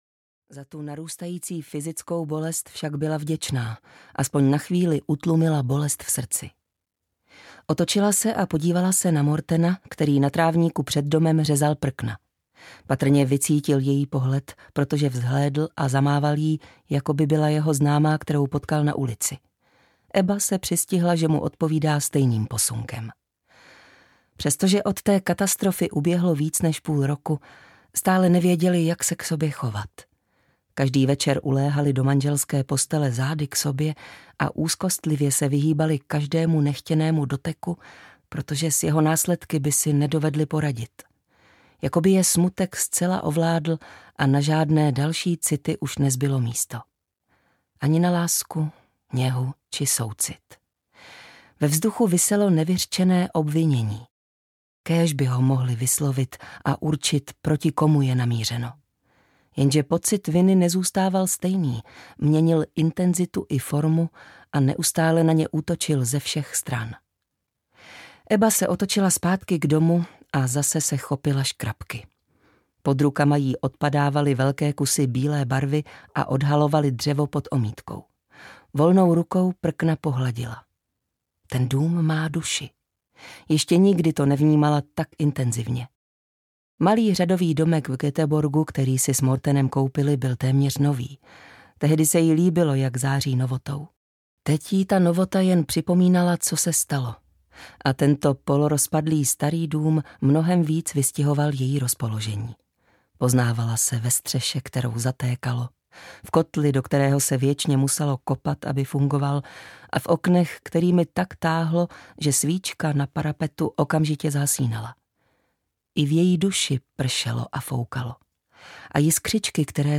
Andělíčkářka audiokniha
Ukázka z knihy